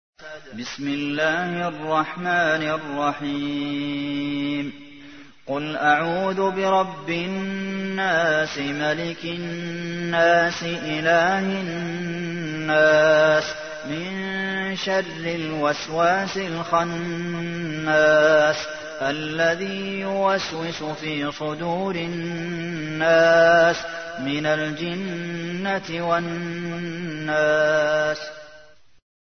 تحميل : 114. سورة الناس / القارئ عبد المحسن قاسم / القرآن الكريم / موقع يا حسين